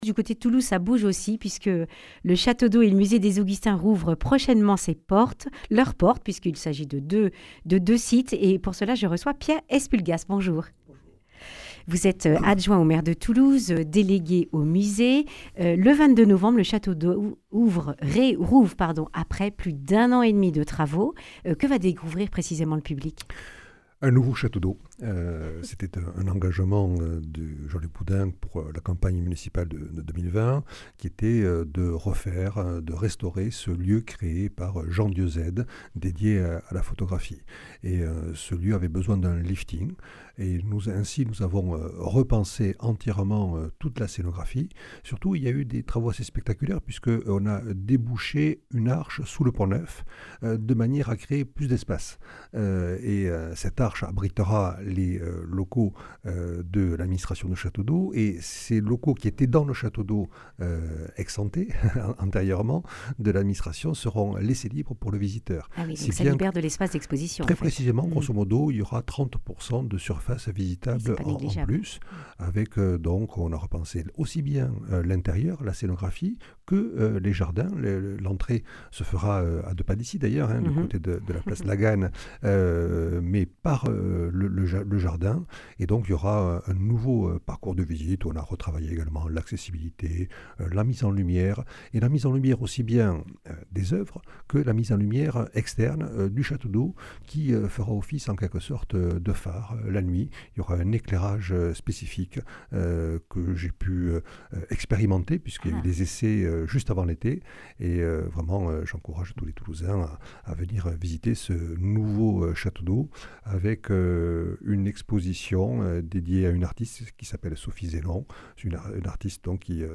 mercredi 22 octobre 2025 Le grand entretien Durée 10 min
Pierre Espulgas, adjoint au maire de Toulouse, délégué aux musées.